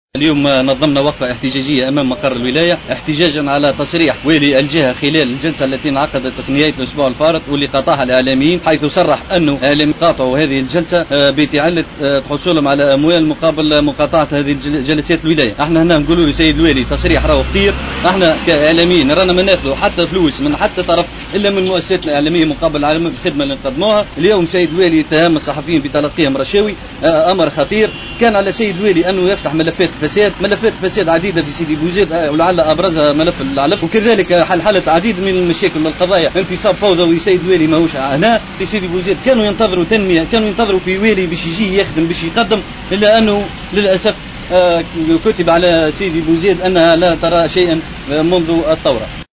أحد المحتجين في تصريح ل"الجوهرة أف أم".